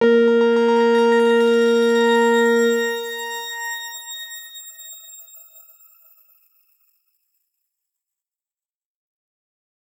X_Grain-A#3-ff.wav